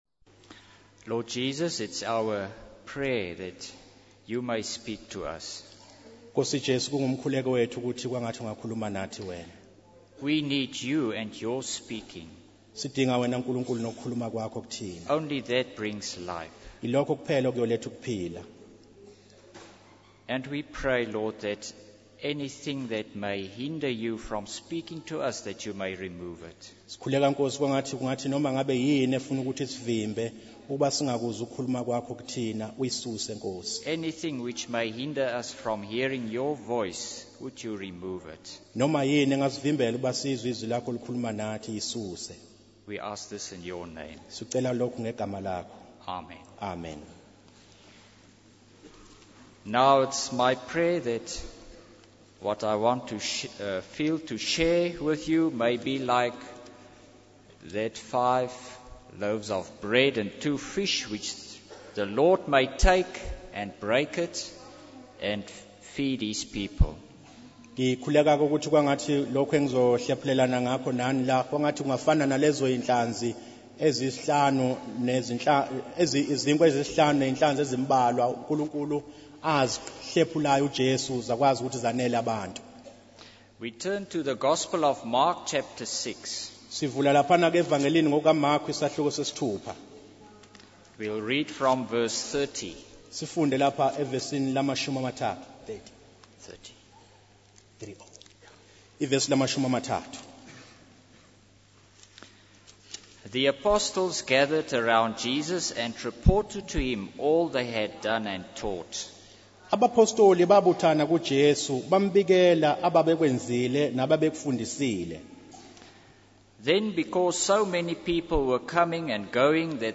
In this sermon, the speaker emphasizes the importance of recognizing the time of grace that God offers to each individual. He uses the analogy of a shepherd and his sheep to illustrate how God knows and cares for each person individually. The speaker also highlights Jesus' compassion for the lost and his willingness to leave heaven and give his life for them.